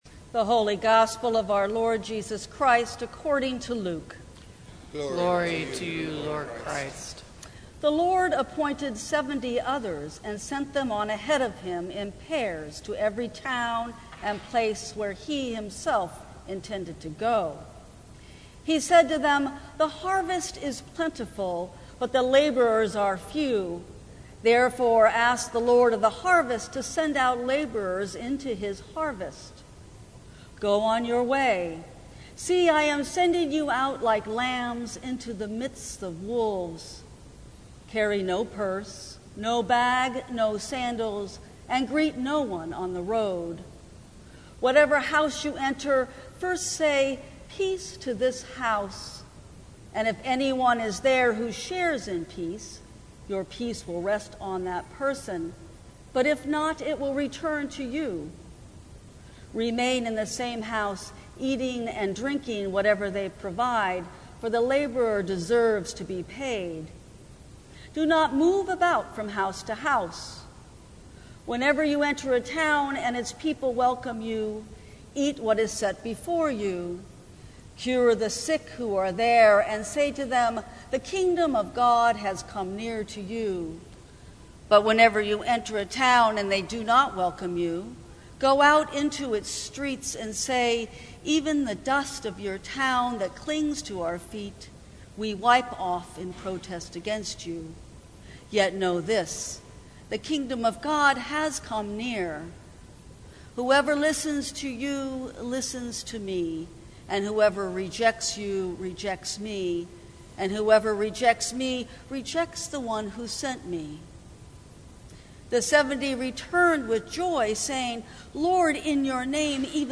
Sermons from St. Cross Episcopal Church Go in Peace Jul 11 2019 | 00:16:07 1x 00:00 / 00:16:07 Subscribe Share Apple Podcasts Spotify Overcast RSS Feed Share Link Embed